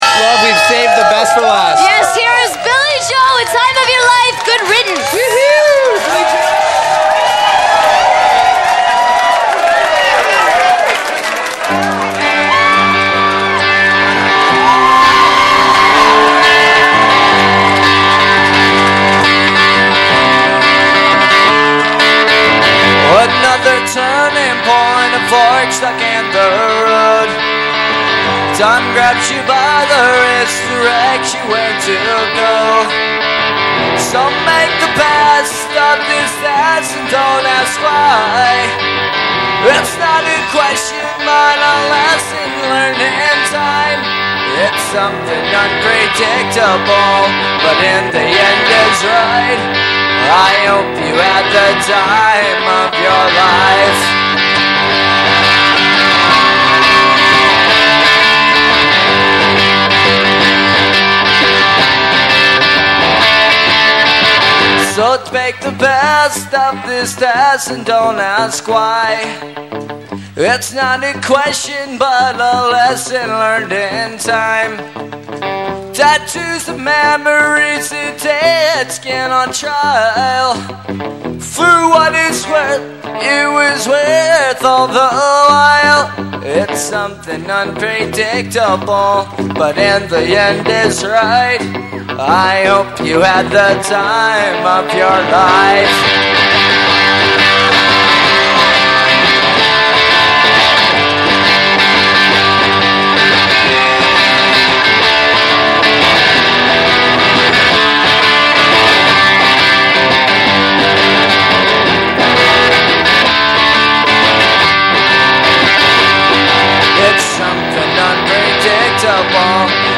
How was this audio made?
a Live version